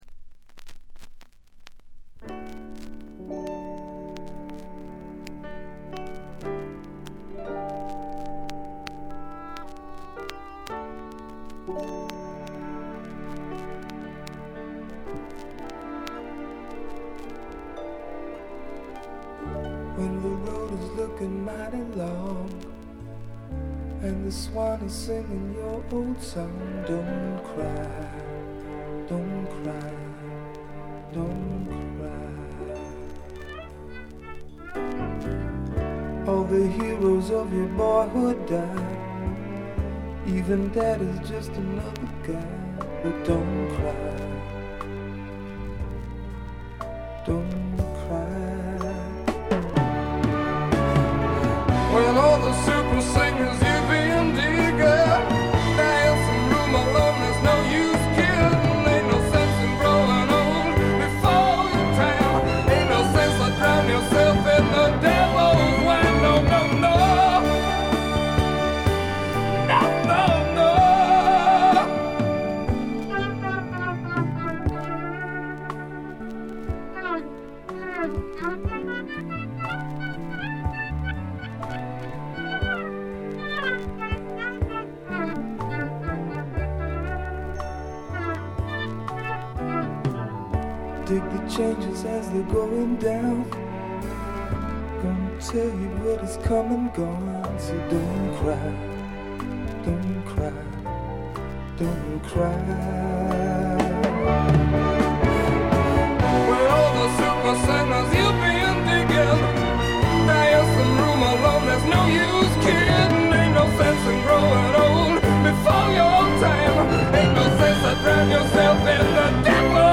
バックグラウンドノイズ、チリプチやや多め（特にA面冒頭）。特別に目立つノイズはありません。
西海岸製スワンプ系シンガーソングライターの裏名盤です。
試聴曲は現品からの取り込み音源です。